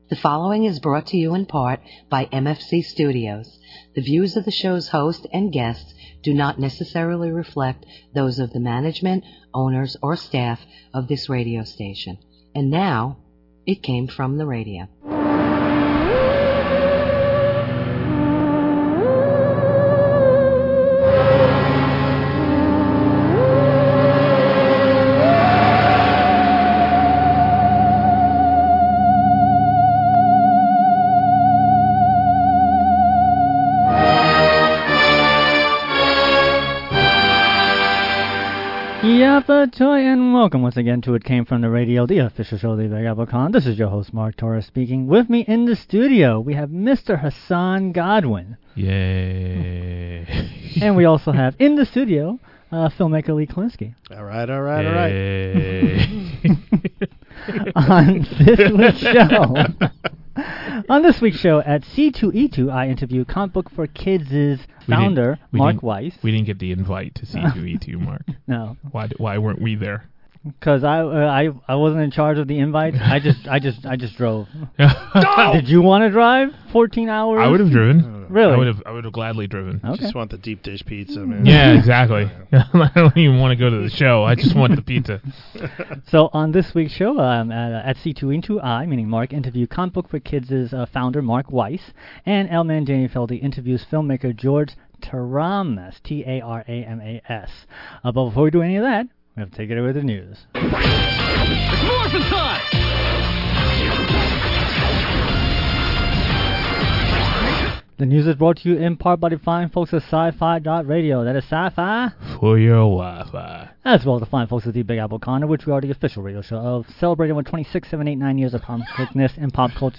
at C2E2
interviews